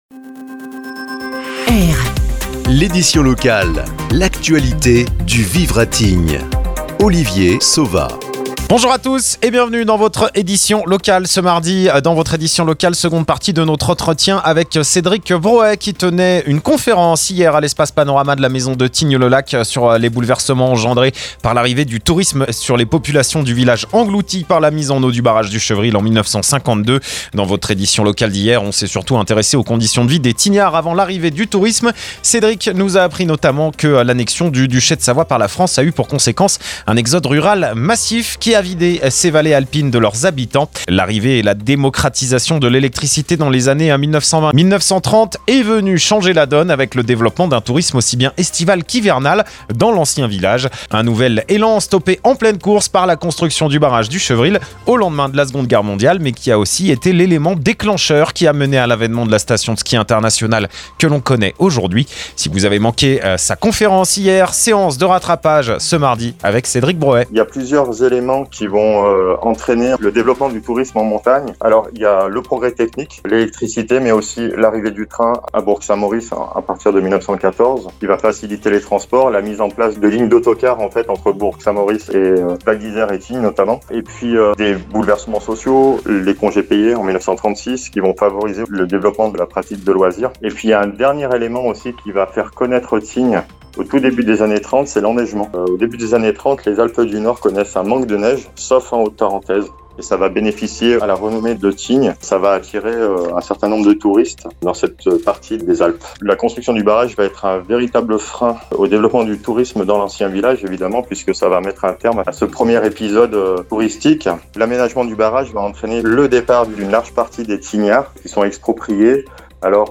AU SOMMAIRE DE L’ÉDITION LOCALE